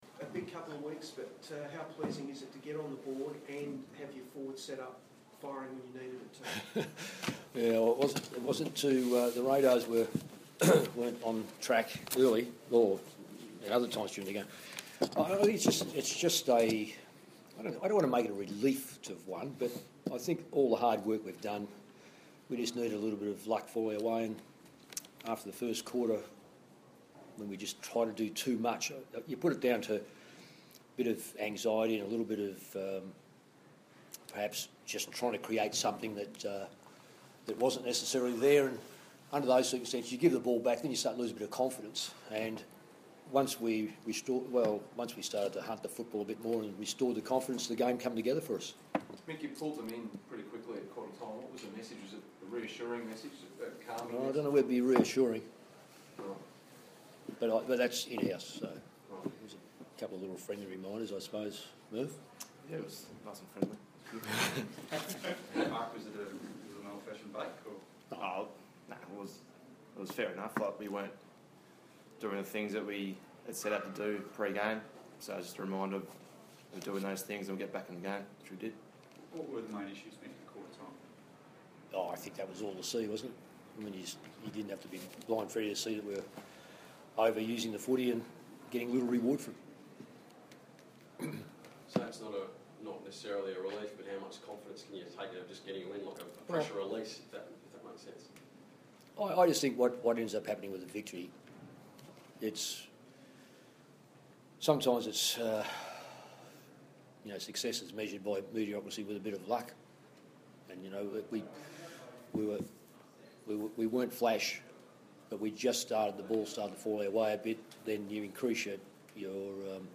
Round 4 post-match press conference
Coach Mick Malthouse and captain Marc Murphy chat to the media after Carlton's 40-point win over the Saints in Wellington, New Zealand.